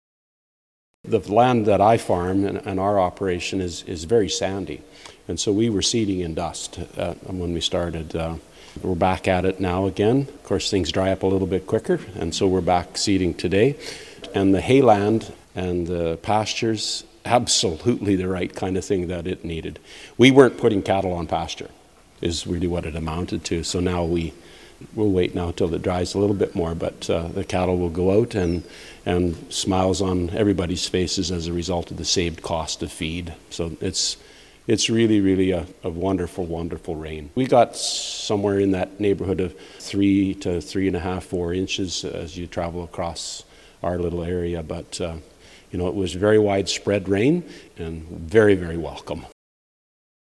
Rain is also welcomed by livestock producers wanting to put their animals out to pasture. Blaine McLeod is a dairy farmer and also the SaskParty MLA for Lumsden-Morse. He calls the rain a godsend.